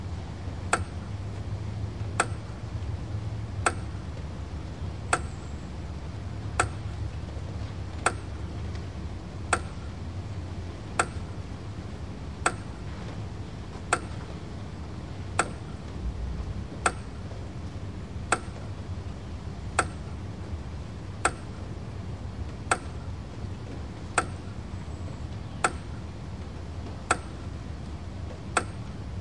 栅栏充电器
描述：在Zoom H2上录制。
这是一个刮风的日子，所以屋顶上有噪音，还有鸟鸣。
Tag: 稳定 农村 录音